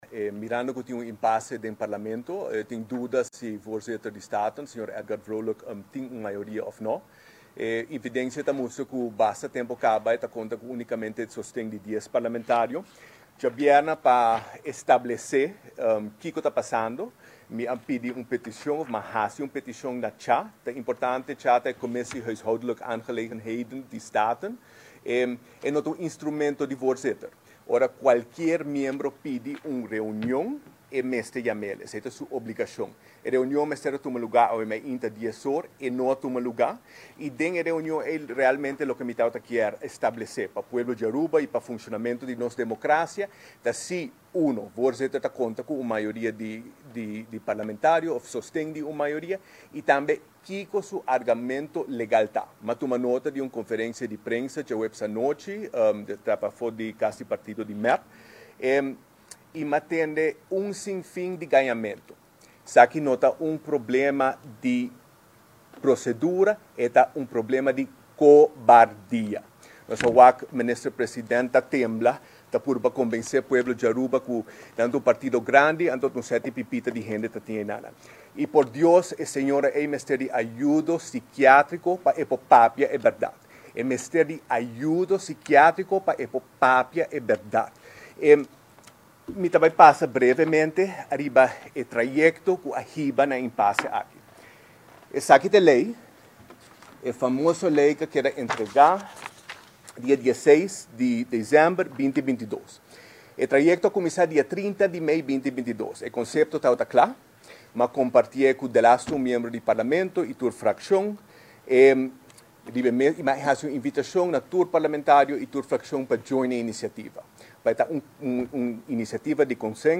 Lider di partido Accion 21 a yama un conferencia di prensa mirando cu e no a ricibi ningun contesta pa e reunion cu a ser pidi pa tuma luga. Segun Lider di accion 21 parlamento ta den un situacion critico mirando cu tin duda riba actual presidente di parlamento Edgar Vrolijk.